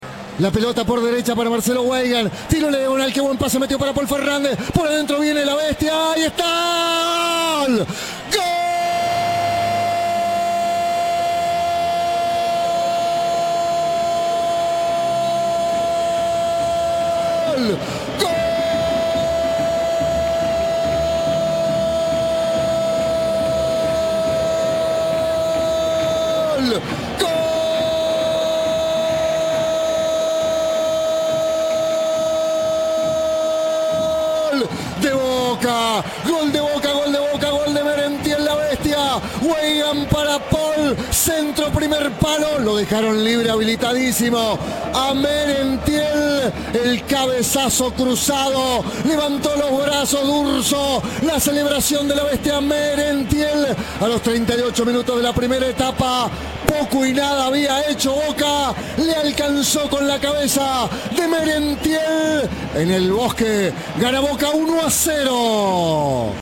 Tridente goleador: reviví el relato de los tres tantos "xeneizes" en El Bosque